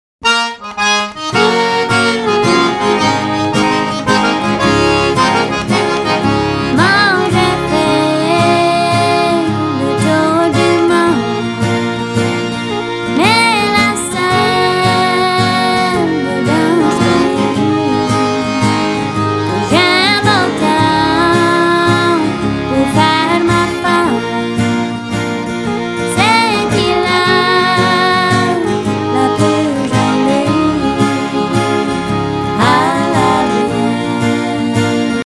Genre: Country & Folk.